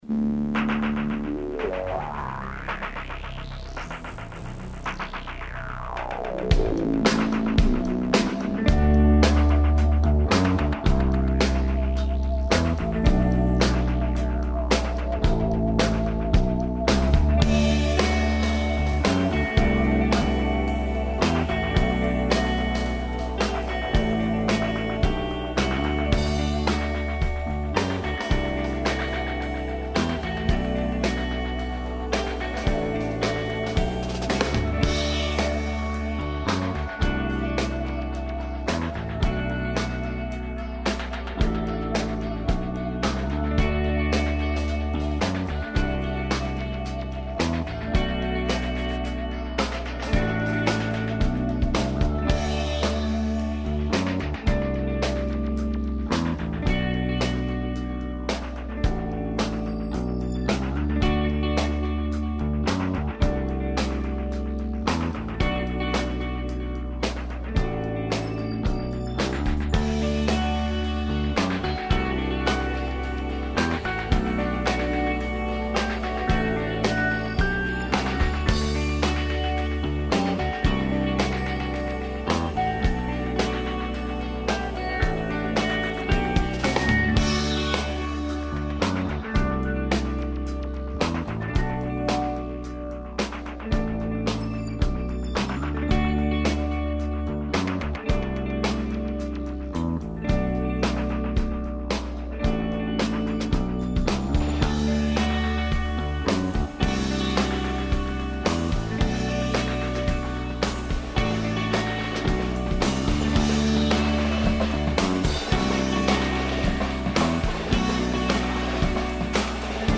Емисију емитујемо из Новог Сада, где данас почиње Међународни музички фестивал 'Еxit'.
Бројни гости емисије преносе све занимљивости везане за овогодишњи "Exit".